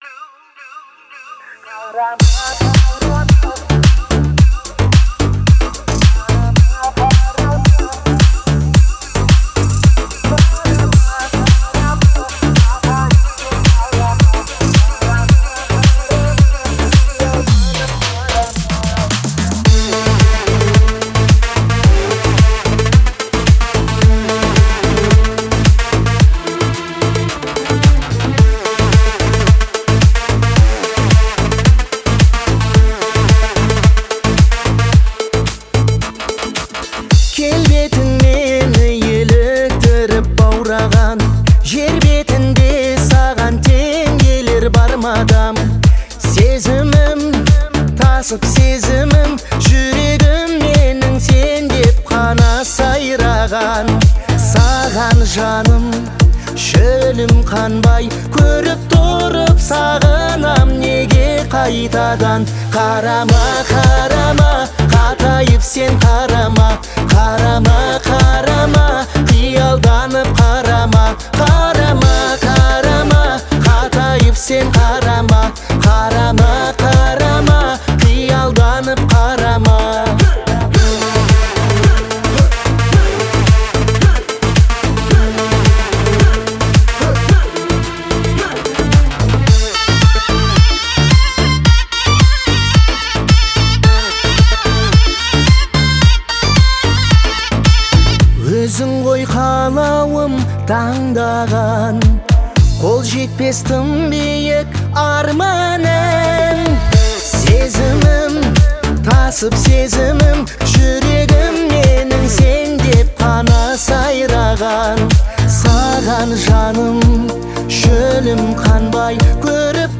это яркая композиция в жанре казахского поп-фолка
обладая выразительным голосом